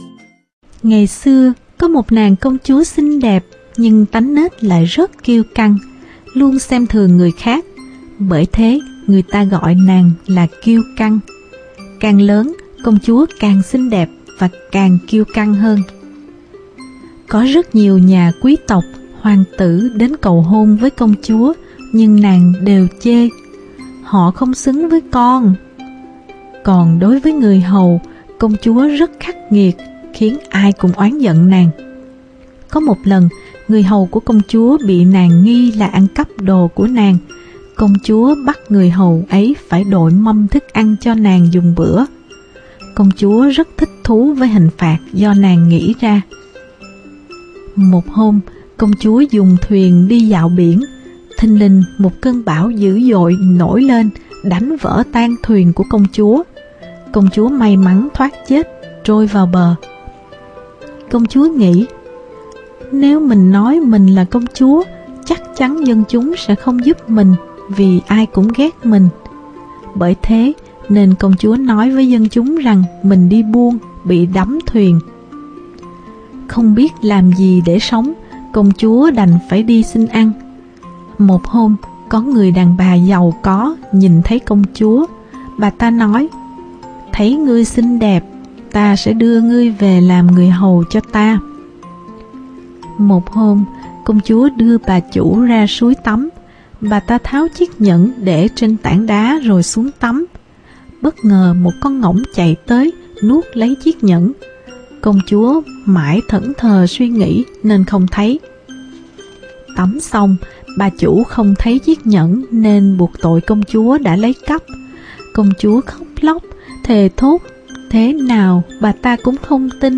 Sách nói | NÀNG CÔNG CHÚA KIÊU CĂNG